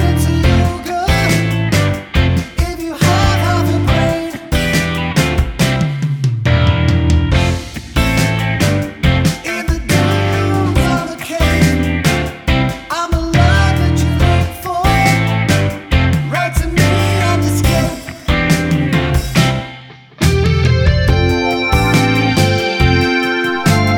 no Backing Vocals Pop (1970s) 4:40 Buy £1.50